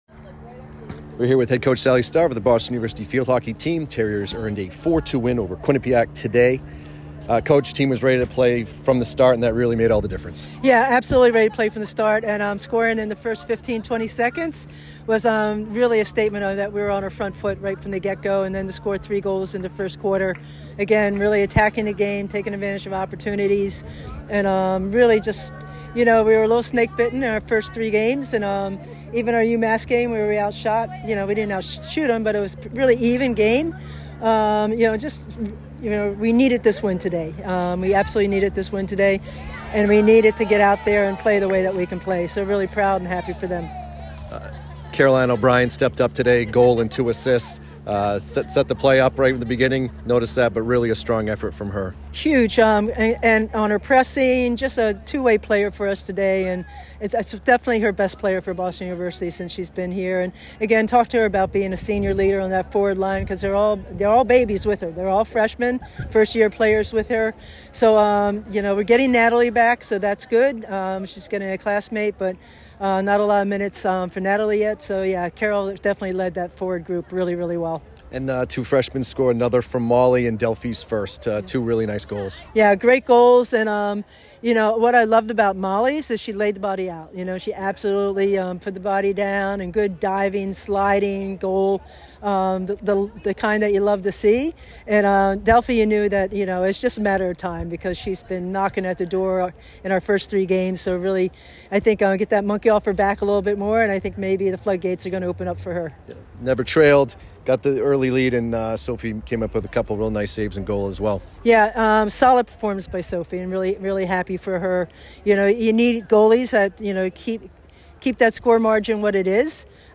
Field Hockey / Quinnipiac Postgame Interview